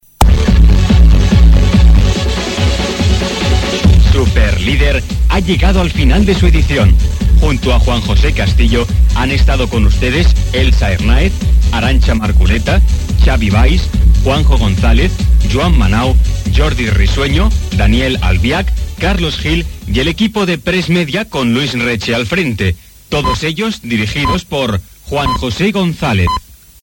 Careta de sortida del programa amb els noms de l'equip
Esportiu